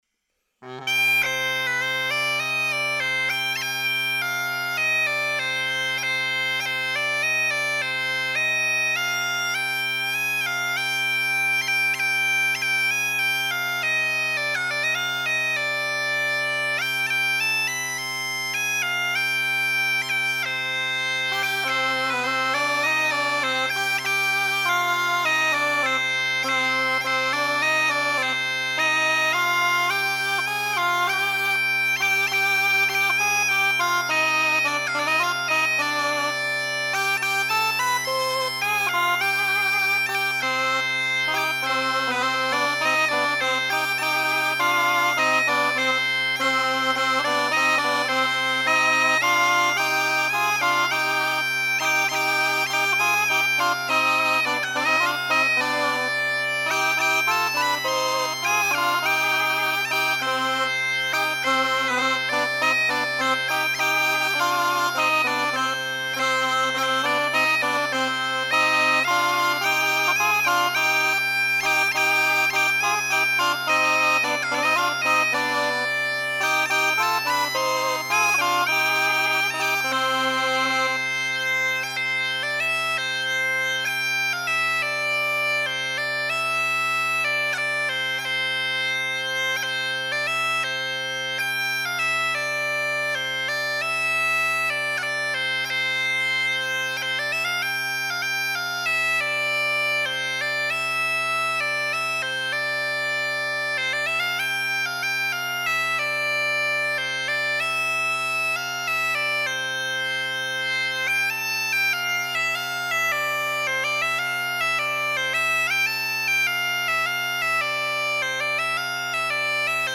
Oiartzungo Lezoti estudioan grabatuta.
Txirimiak, txistu eta danbolina